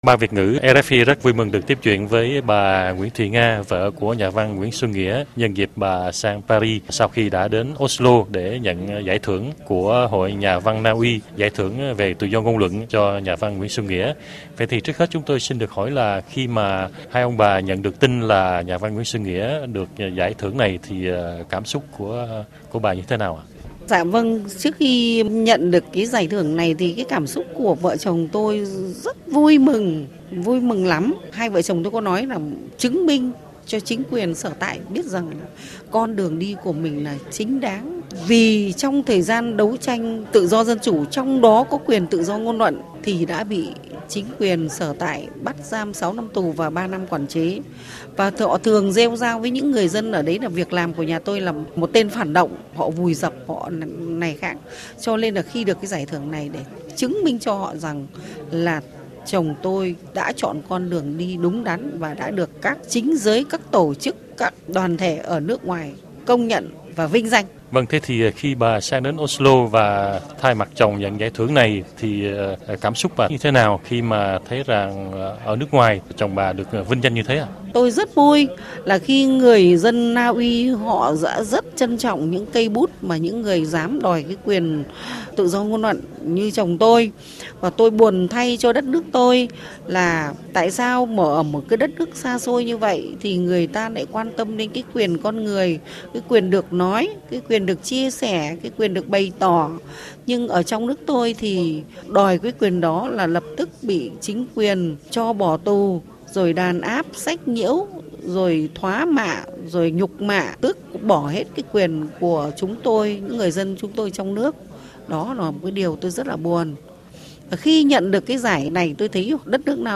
Nhân dịp này bà đã dành cho RFI Việt ngữ một bài phỏng vấn ngắn.